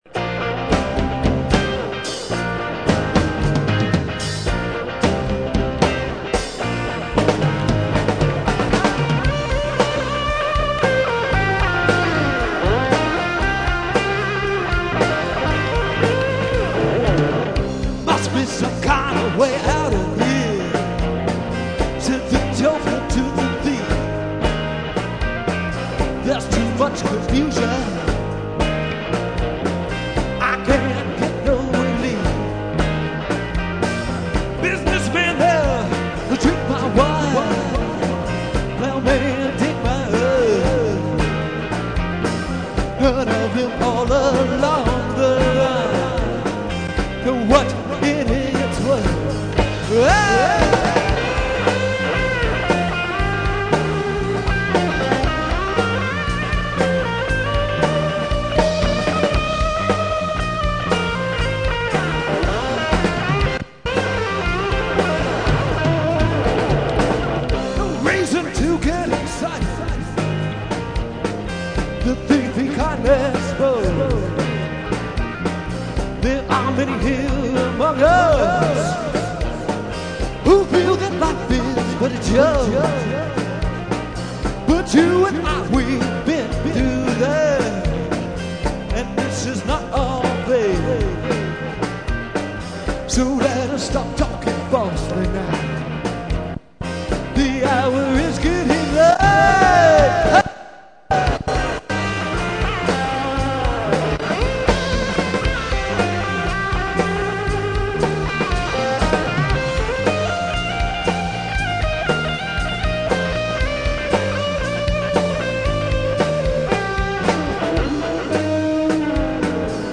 ROCK 'N ROLL